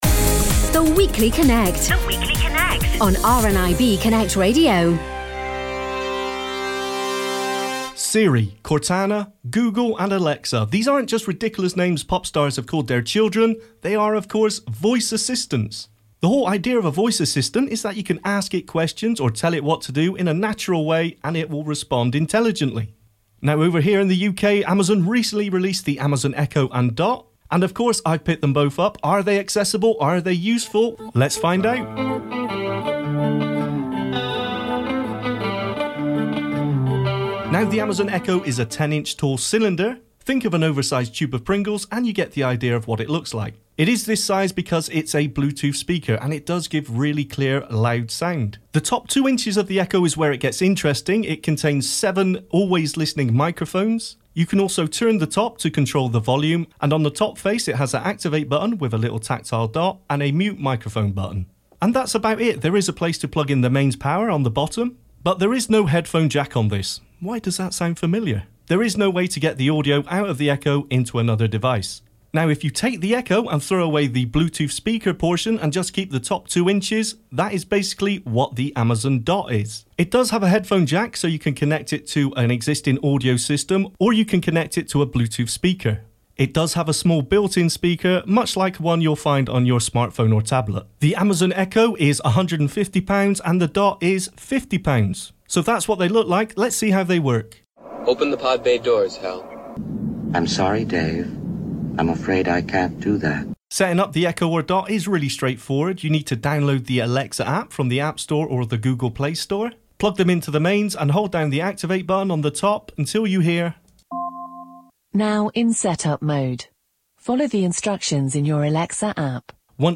Technology Review: Amazon Echo